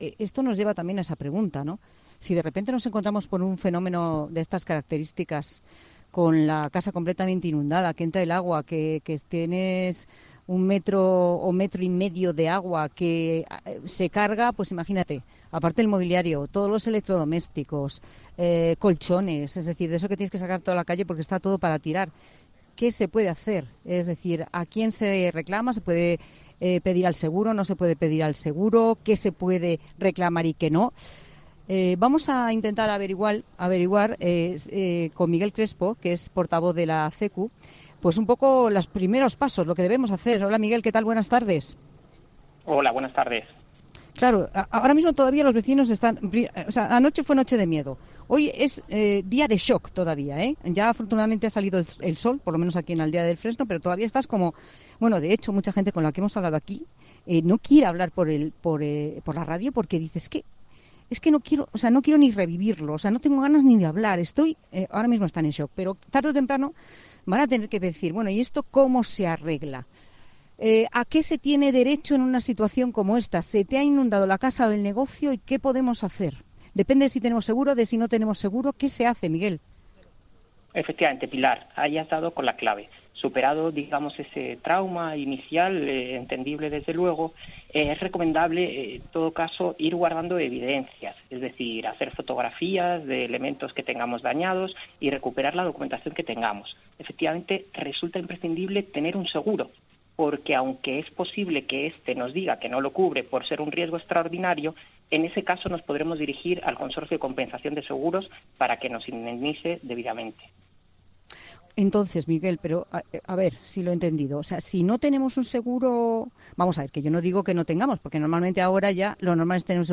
En 'La Tarde' hablamos con un experto que da las claves sobre cómo reclamar los daños en tu casa o tu negocio tras la DANA y cómo iniciar el trámite